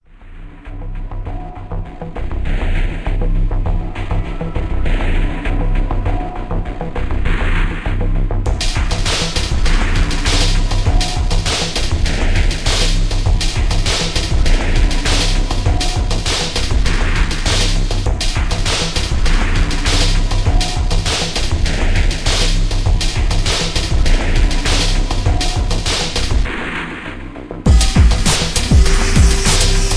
Dark semi industrial style track.
Moody and cinematic.
Tags: ambient, tribal